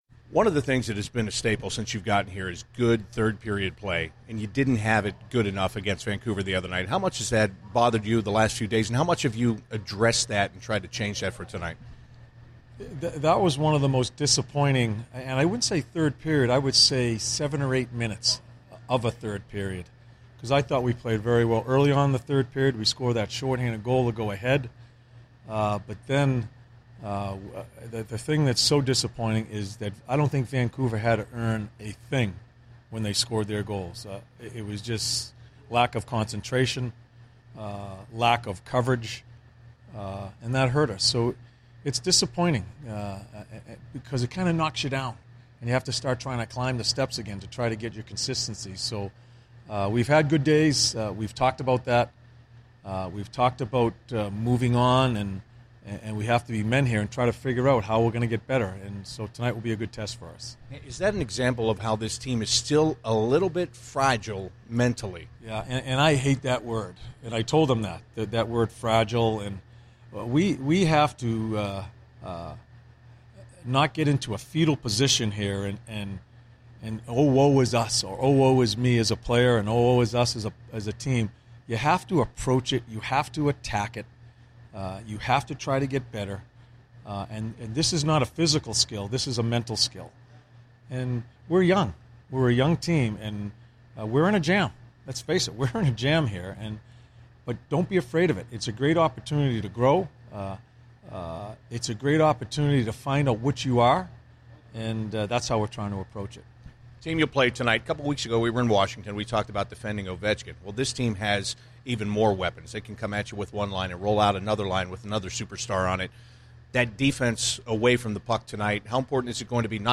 CBJ Interviews / John Tortorella Pre-Game 11/13/15